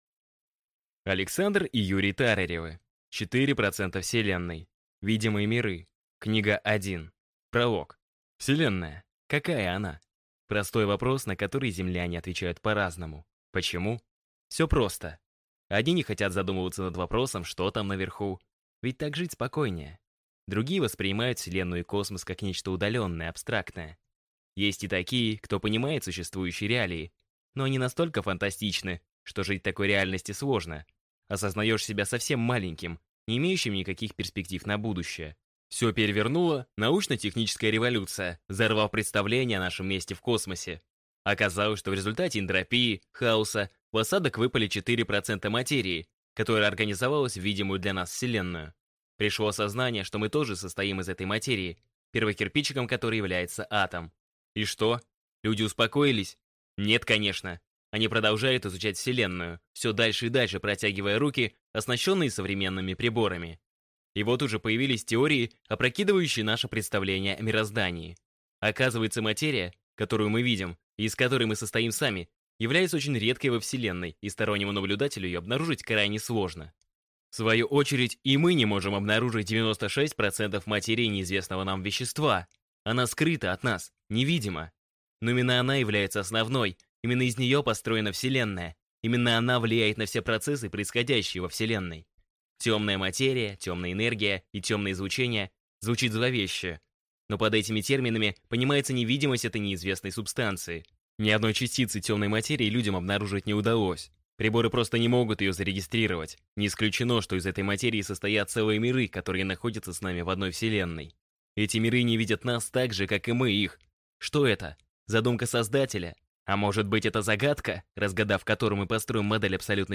Аудиокнига Четыре процента Вселенной. Видимые миры. Книга 1 | Библиотека аудиокниг